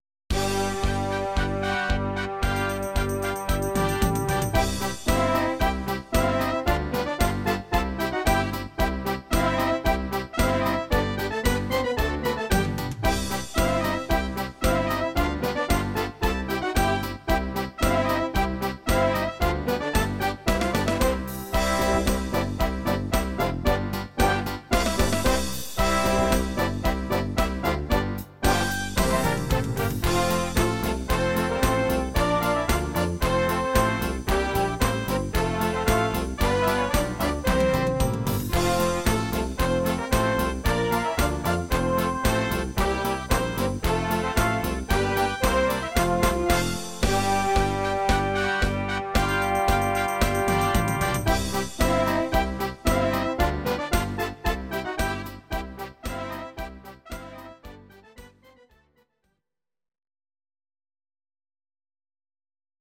These are MP3 versions of our MIDI file catalogue.
instr. Orchester